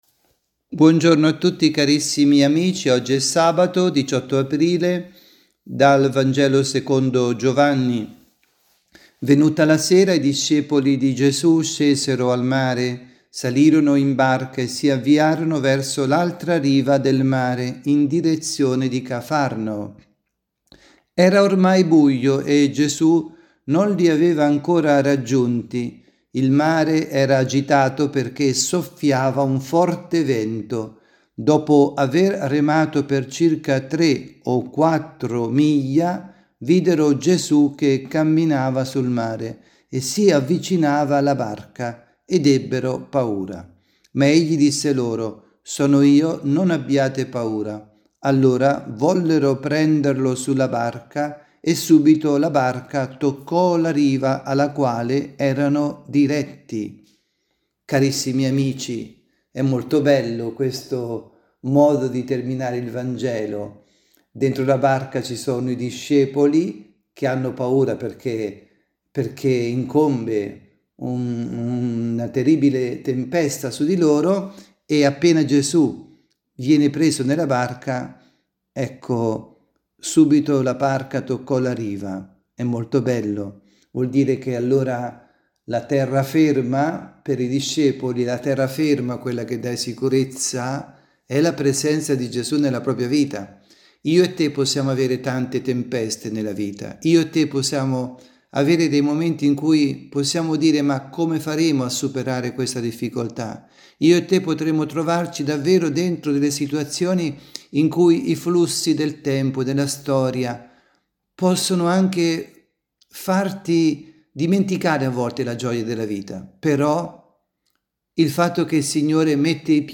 avvisi, Catechesi, Omelie, Pasqua
dalla Basilica di San Nicola – Tolentino